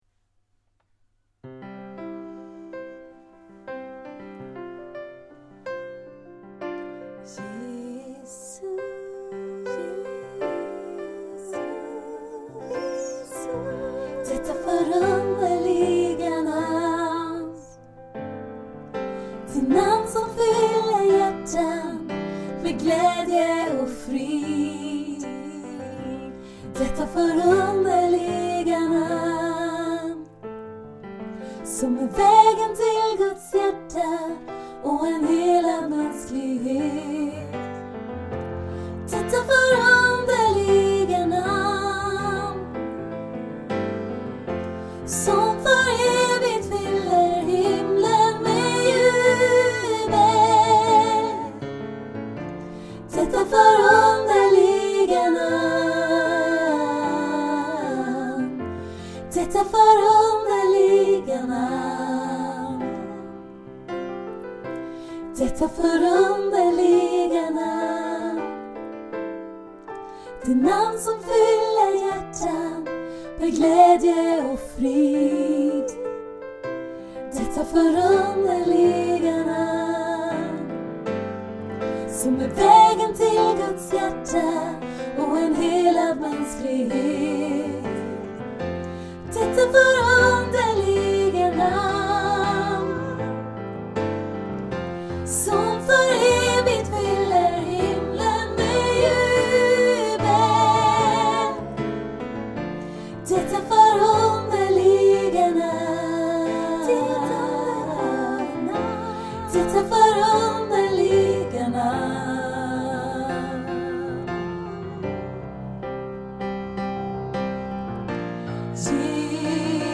Här finns några moderna nya psalmer i enkla inspelningar.